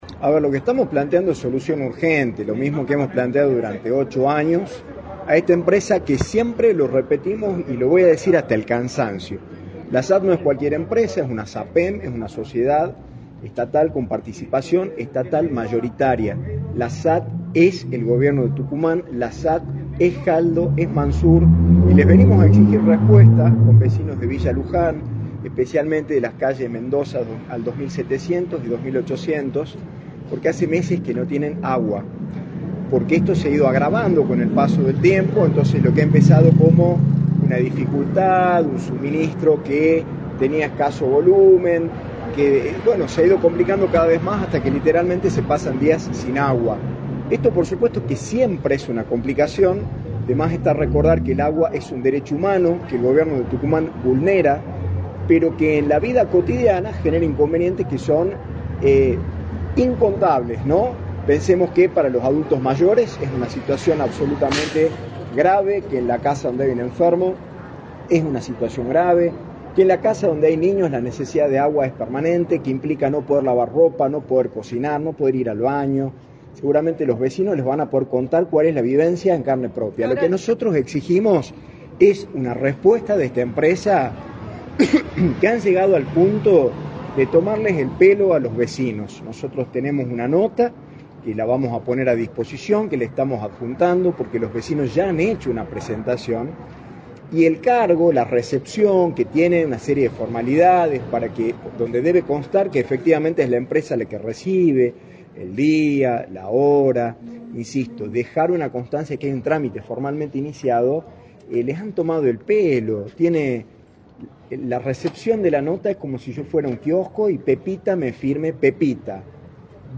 “La SAT es Manzur y Jaldo, le pedimos a este gobierno que dé respuestas, no se puede vivir en condiciones infrahumanas, exigimos que dejen de tomarle el pelo a los vecinos, que hace tiempo vienen a presentar notas, juntando firmas, y se llevaron una firma sin un número de expediente, les faltan el respeto” señaló Canelada en Radio del Plata Tucumán, por la 93.9.